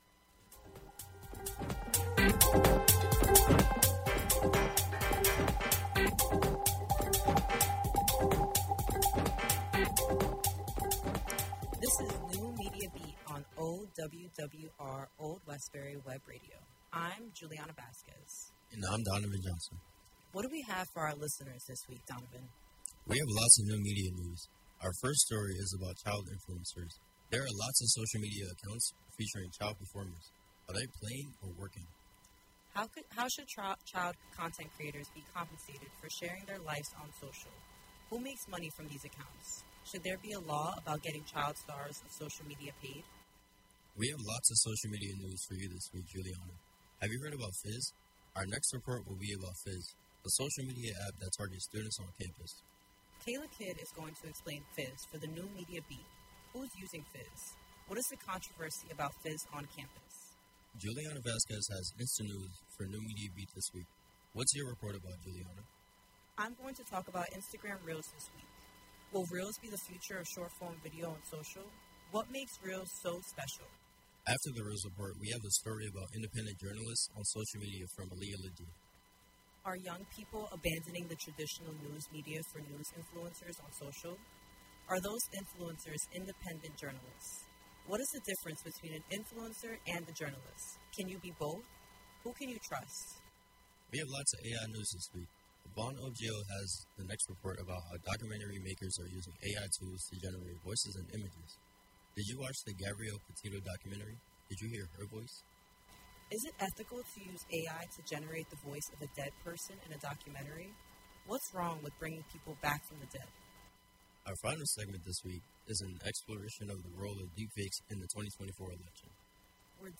New Media Beat covers new media news from around the world. NMB is a production of Media Studies students from the SUNY College @ Old Westbury.